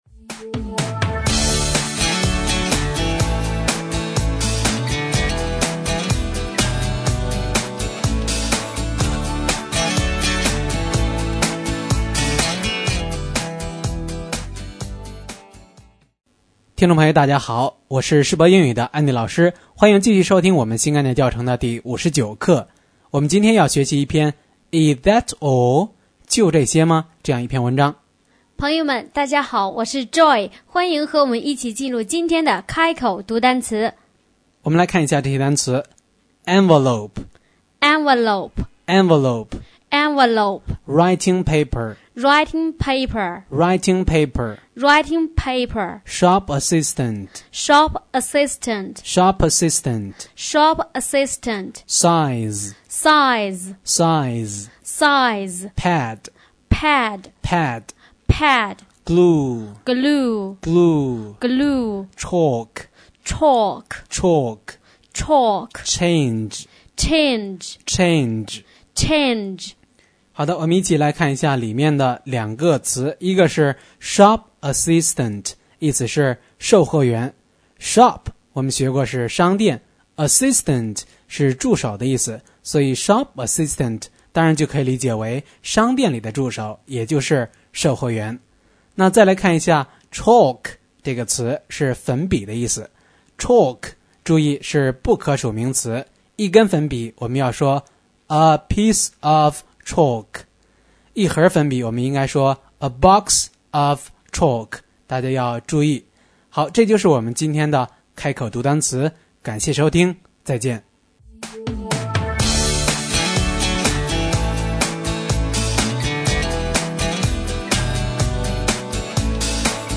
开口读单词